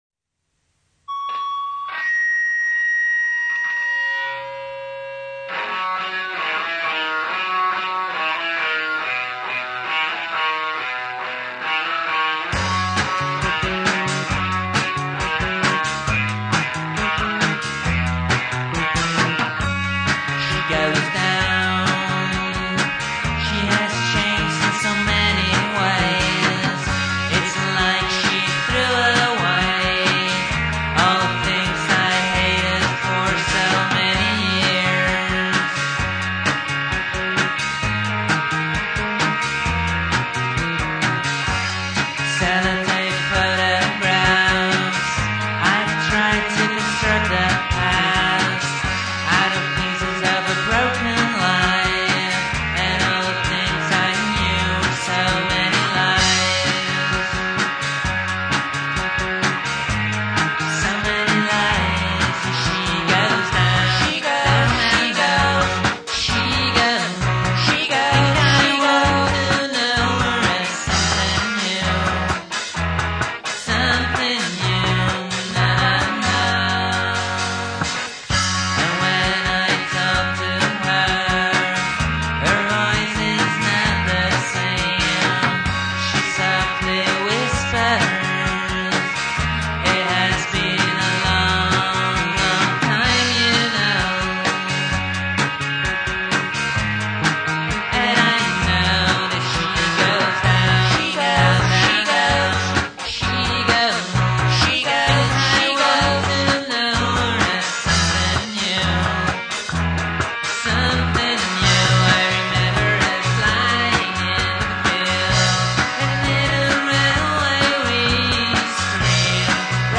where: recorded at AMP (Amsterdam)